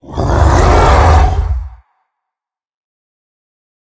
assets / minecraft / sounds / mob / ravager / roar3.ogg
roar3.ogg